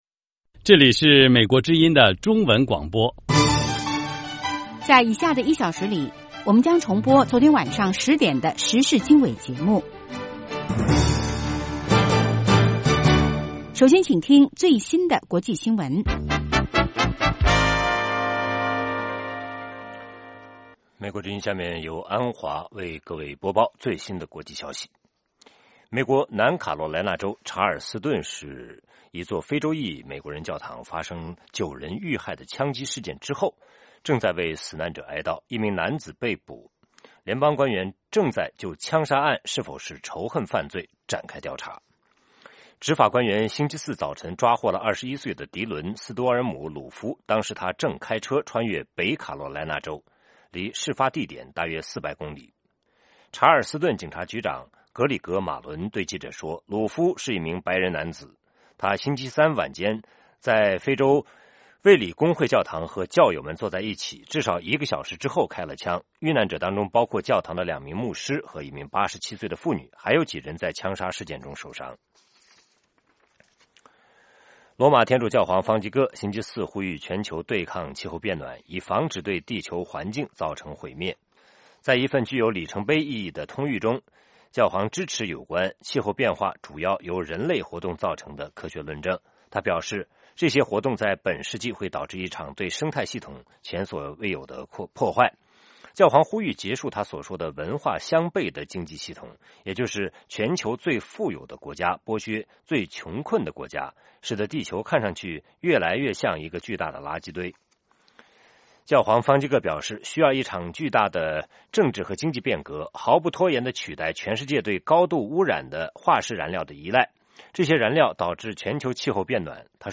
北京时间早上8-9点广播节目 这个小时我们播报最新国际新闻，并重播前一天晚上10-11点的时事经纬节目。